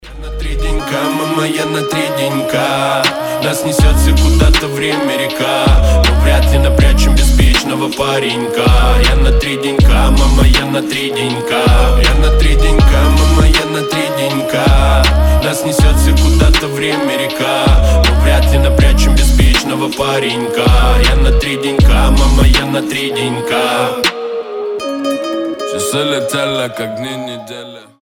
• Качество: 320, Stereo
Хип-хоп
грустные